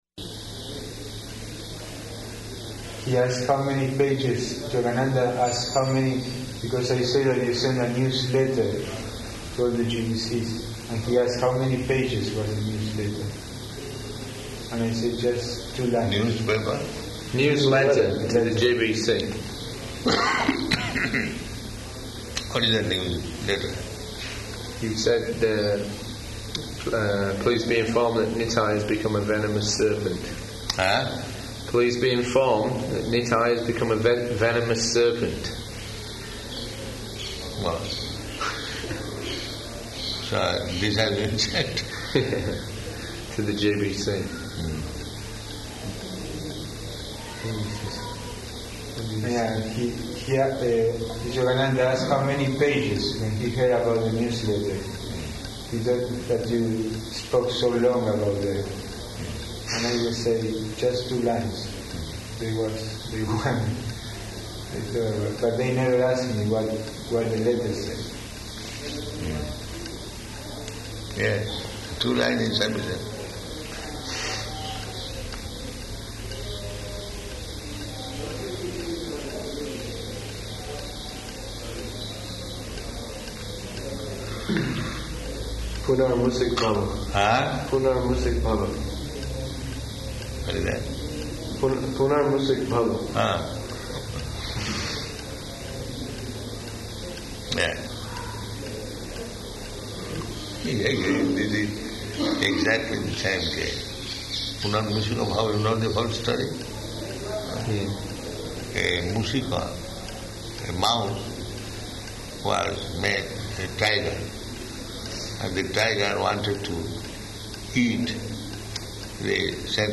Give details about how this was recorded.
Location: Vṛndāvana